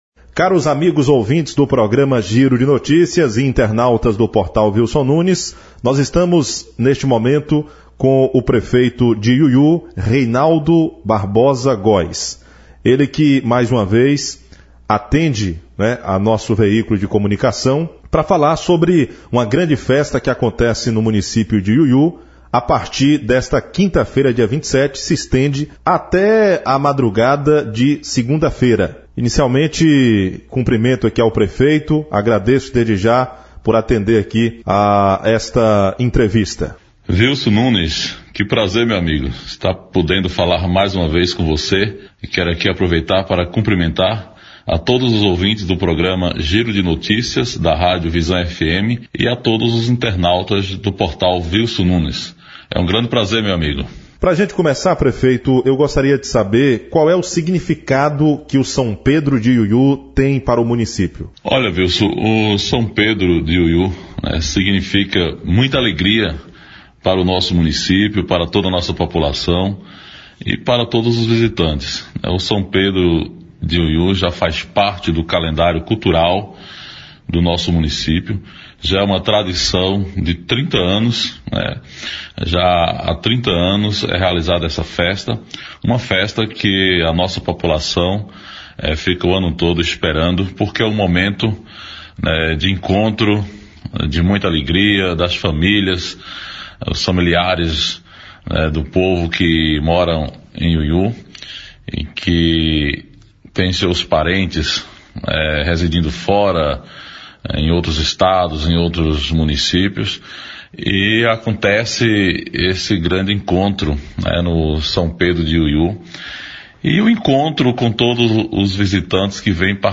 Ainda durante a sua fala, Reinaldo abordou qual o significado que o São Pedro tem para o município de Iuiu; como foi planejada a programação musical; reforço da Segurança, entre outros assuntos. OUÇA A ENTREVISTA NA ÍNTEGRA:
Entrevista-com-o-prefeito-Reinaldo-de-Iuiu-site.mp3